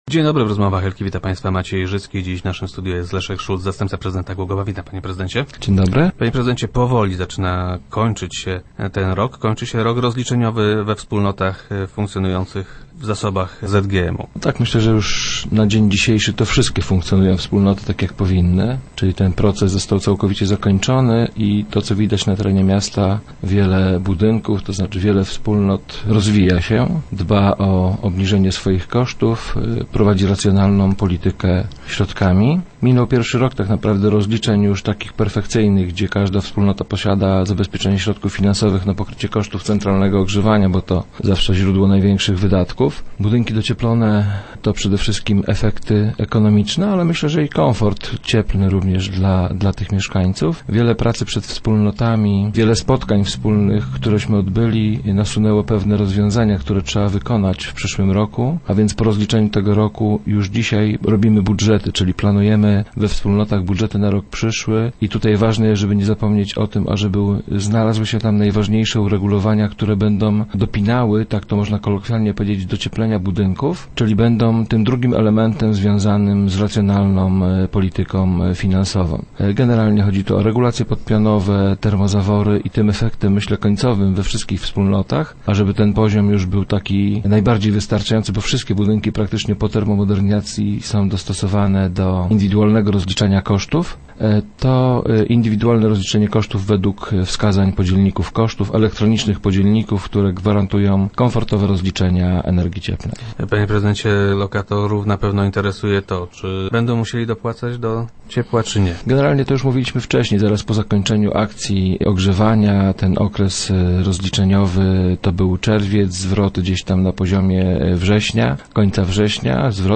- Zdecydowana większosć z nic rozwija się i dba o obniżenie swych kosztów - twierdzi Leszek Szulc, zastępca prezydenta Głogowa, który był gościem Rozmów Elki.